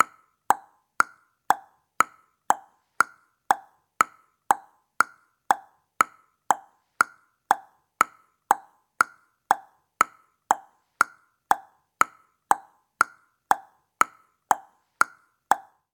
cartoon_clock_ticking